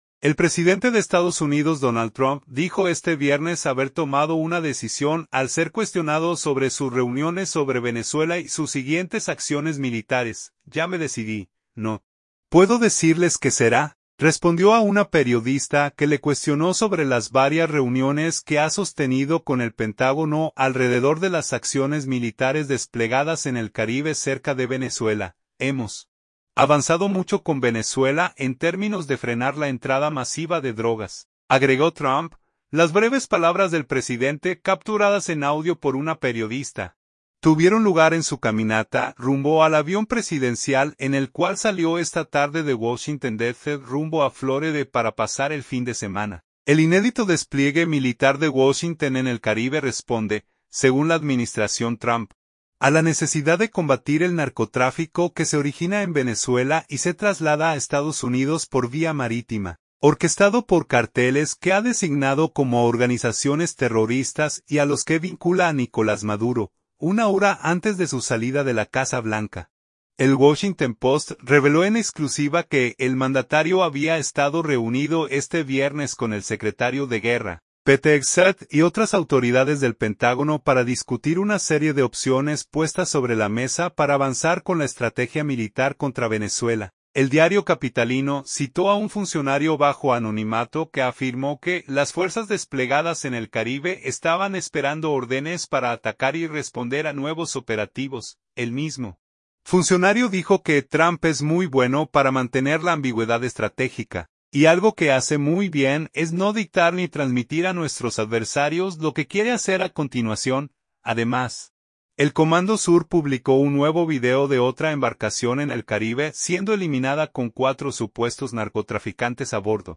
“Ya me decidí. No puedo decirles qué será”, respondió a una periodista que le cuestionó sobre las varias reuniones que ha sostenido con el Pentágono alrededor de las acciones militares desplegadas en el Caribe cerca de Venezuela.
Las breves palabras del presidente, capturadas en audio por una periodista, tuvieron lugar en su caminata rumbo al avión presidencial en el cual salió esta tarde de Washington D.C. rumbo a Florida para pasar el fin de semana.